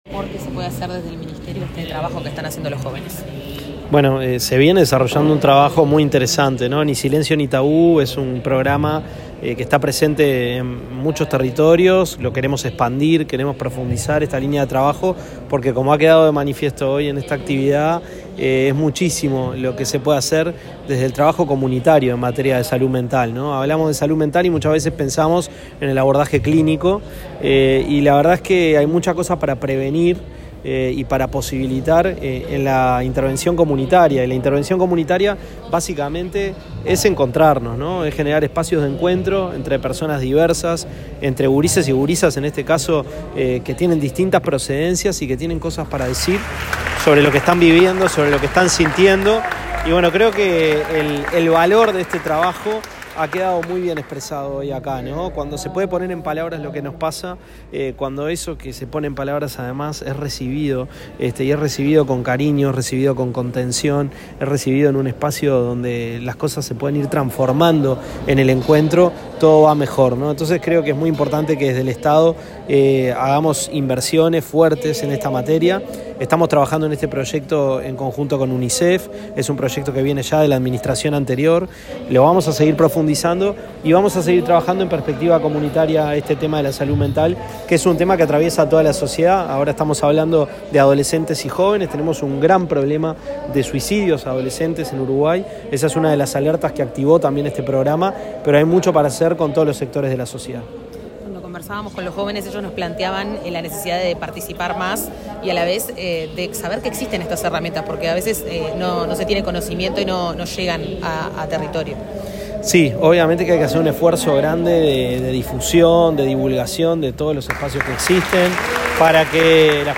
Autoridades del Ministerio de Desarrollo Social estuvieron presentes en la actividad celebrada en la Casa INJU bajo el nombre “En voz alta: juventudes y bienestar emocional” con el objetivo de visibilizar, reflexionar y dialogar sobre el bienestar emocional de adolescentes y jóvenes.
Audio del ministro de Desarrollo Social, Gonzalo Civila, y la directora del Instituto Nacional de la Juventud, Eugenia Godoy.